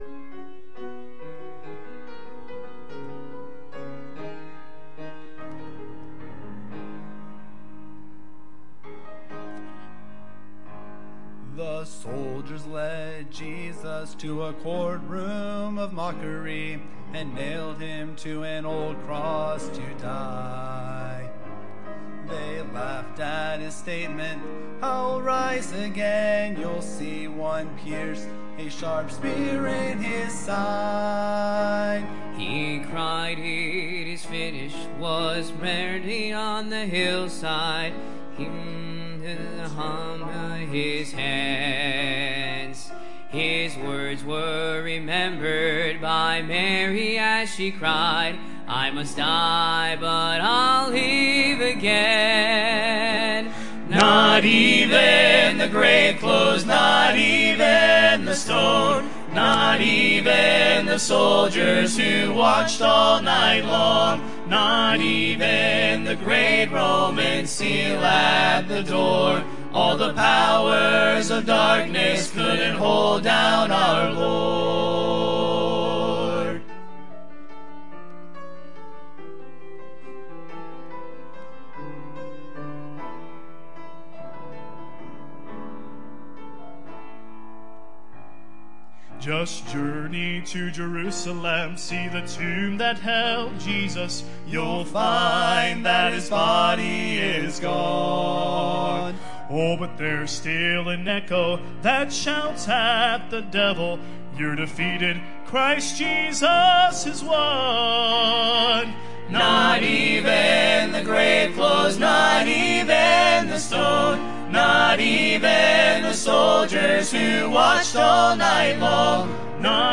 The Risen Saviour | Sunday AM